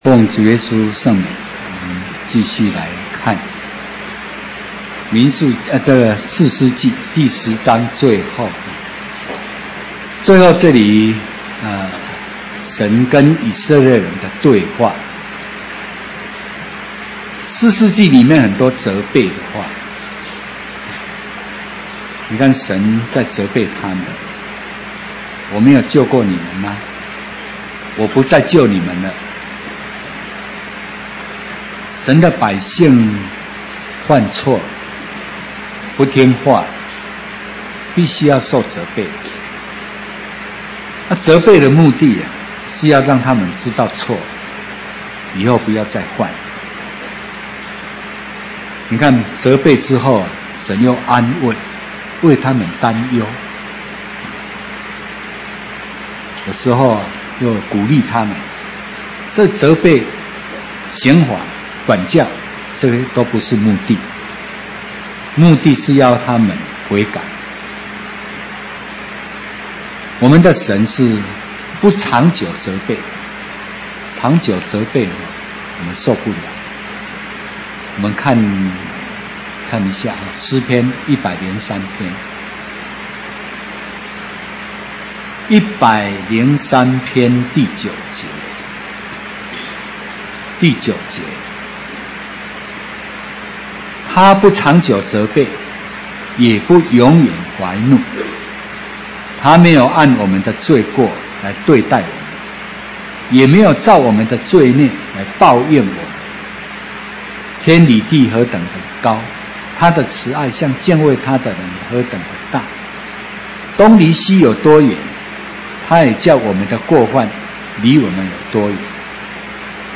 講習會
地點 台灣總會 日期 02/14/2016 檔案下載 列印本頁 分享好友 意見反應 Series more » • 士師記 20-1 • 士師記 20-2 • 士師記 20-3 …